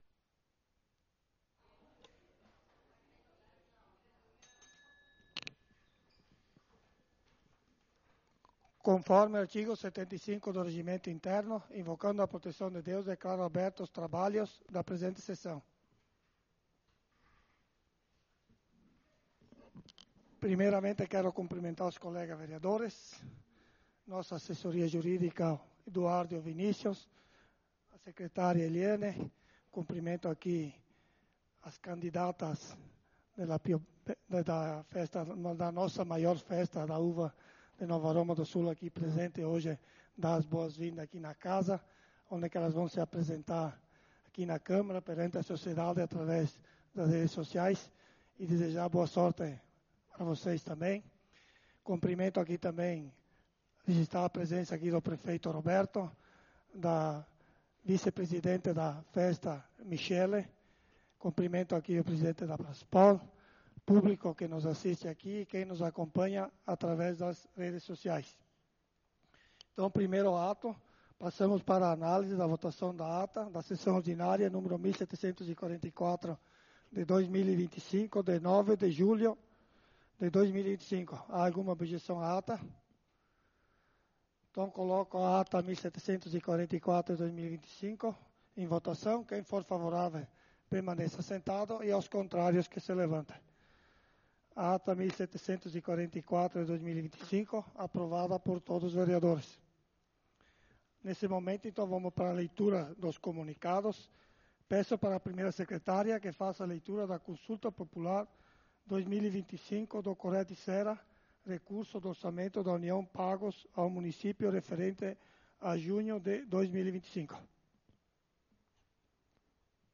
Sessão ordinária do dia 16/07/2025